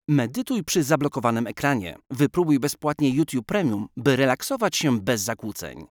Commercieel, Vriendelijk, Warm, Zacht, Zakelijk
Commercieel